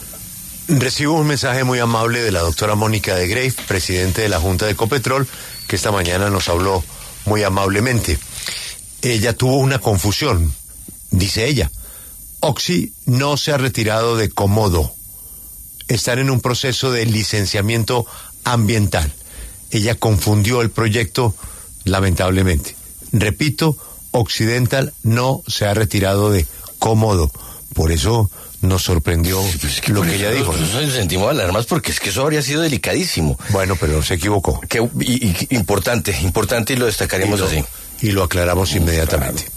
Mónica de Greiff, presidenta de la junta directiva de Ecopetrol, conversó con La W sobre el futuro de la exploración de hidrocarburos en el país.